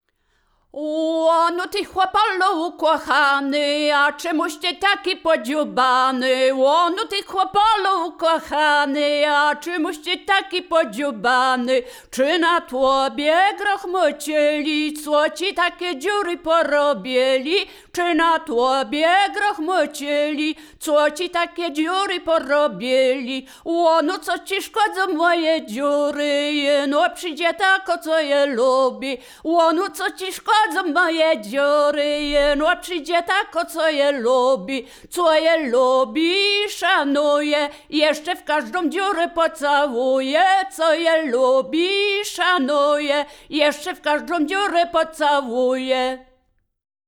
liryczne miłosne żartobliwe przyśpiewki